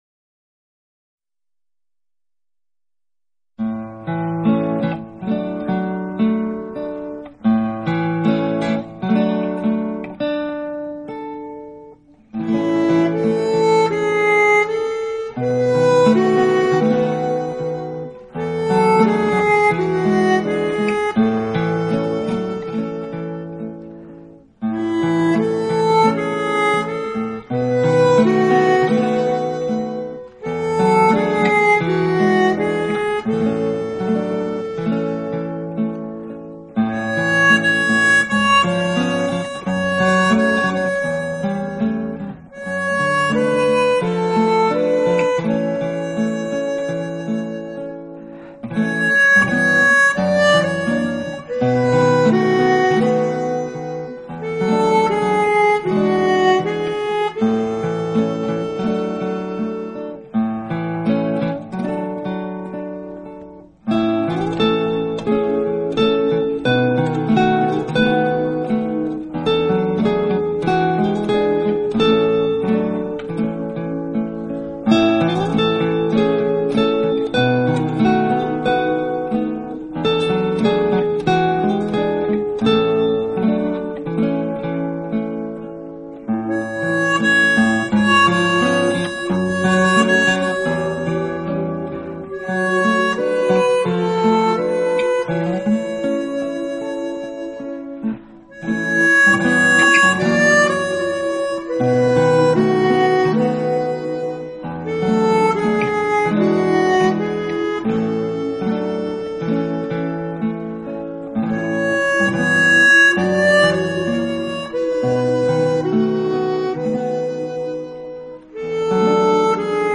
音色奇美